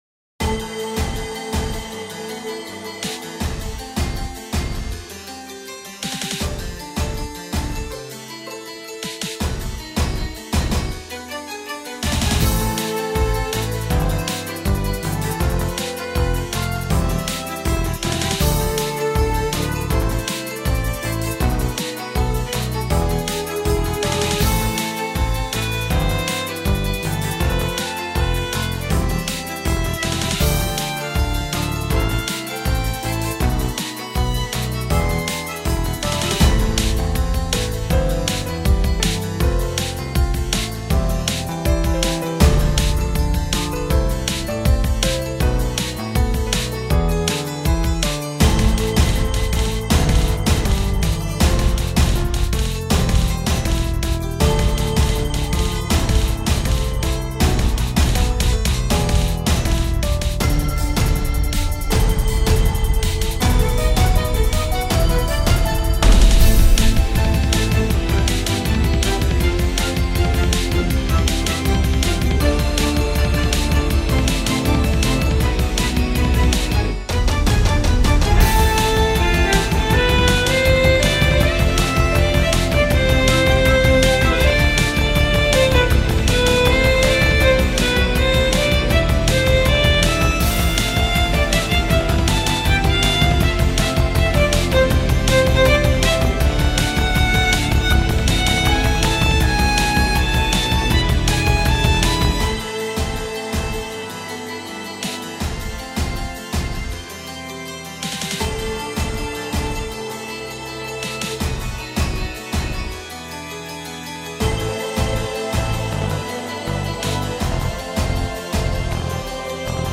「光と闇の対比」をテーマに制作したダーク・クラシカル系のインスト。
• テンポ：ゆったりとしたBPM（静と動の落差を際立たせる）